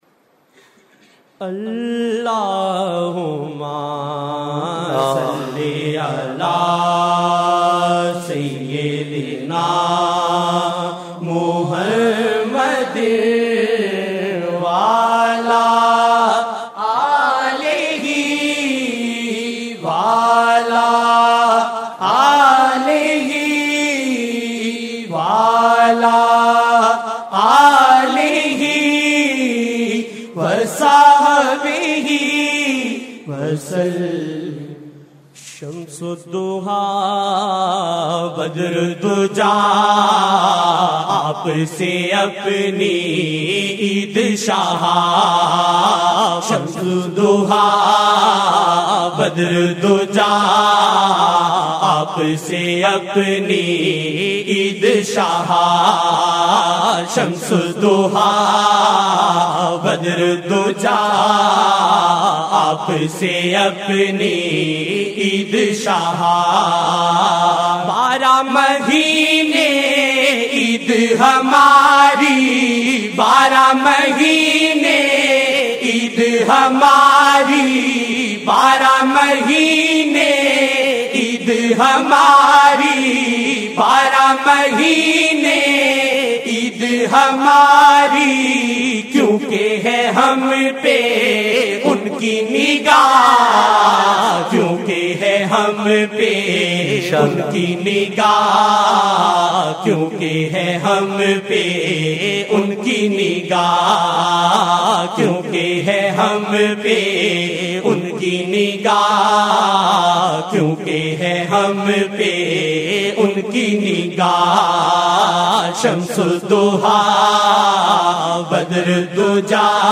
silsila ameenia | » Naat-e-Shareef